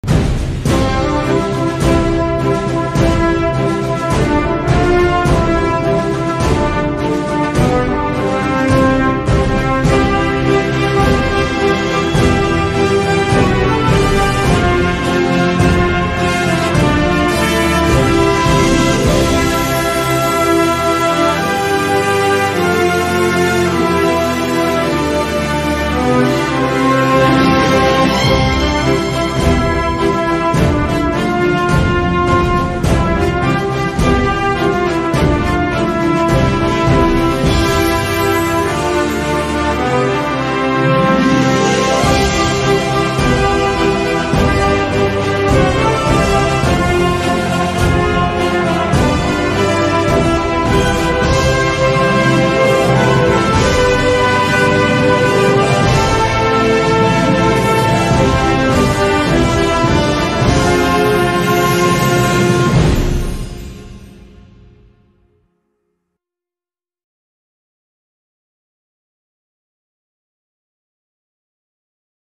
Hymne Indien
INDE-hymne.mp3